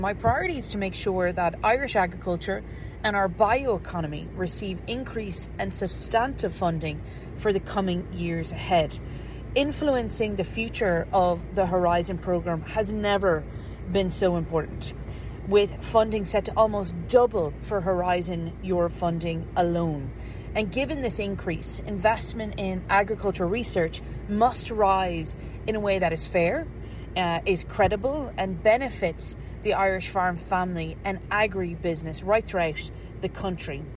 MEP Walsh says she plans to prioritise Irish farming families and businesses in securing funding: